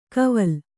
♪ kaval